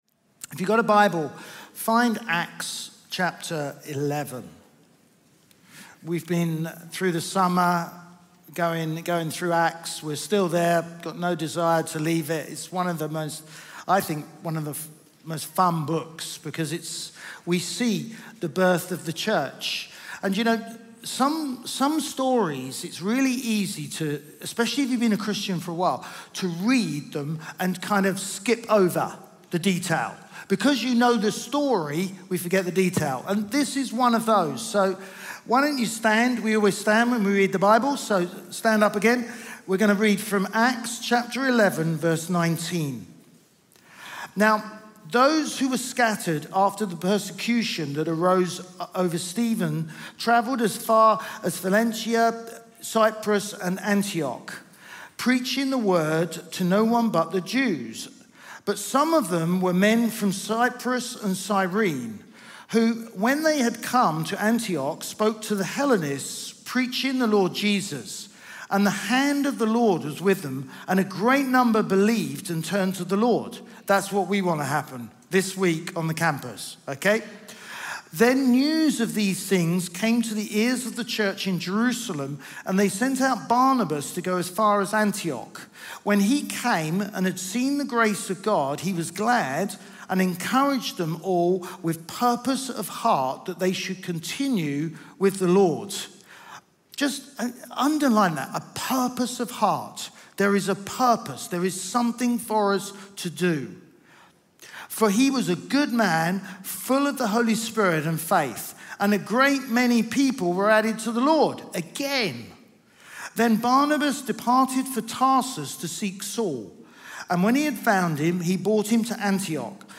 Chroma Church Live Stream
Chroma Church - Sunday Sermon